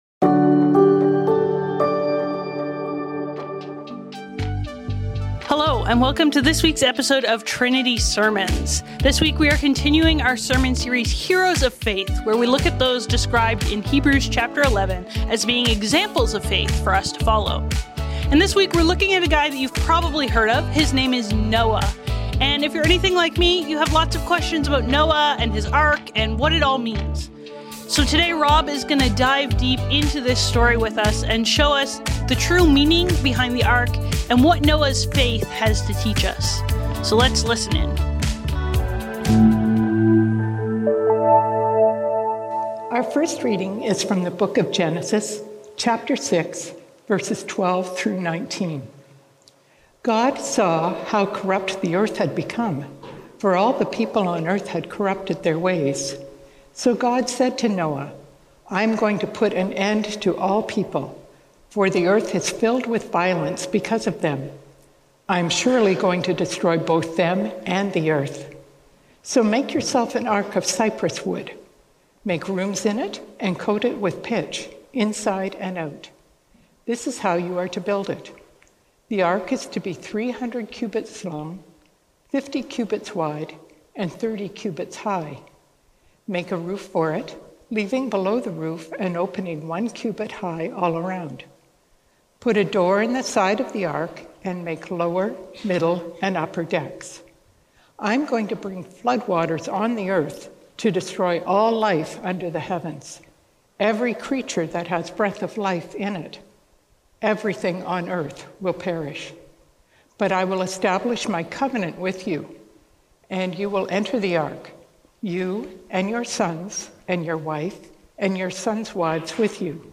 Trinity Streetsville - Noah - A Faith that Floats | Heroes of Faith | Trinity Sermons